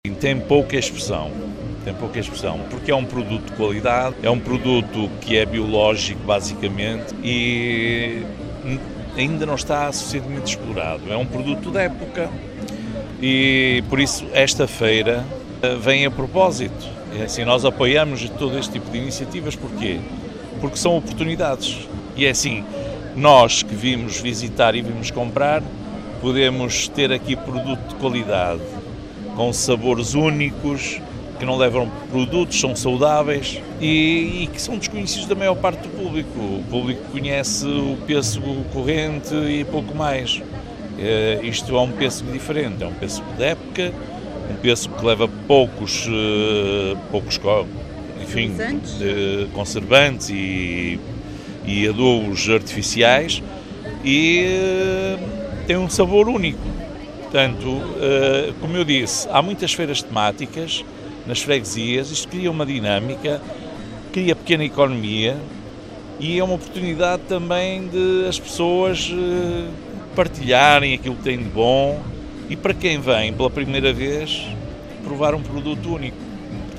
No domingo, decorreu uma caminhada solidária a reverter a favor da Liga Portuguesa Contra o Cancro de 14 quilómetros, que contou com 70 participantes e um seminário de Boas Práticas agrícolas, em que esteve o Presidente da Câmara Municipal de Macedo de Cavaleiros, Benjamim Rodrigues, que elogia este tipo de feiras, e esta com especial destaque para um produto biológico: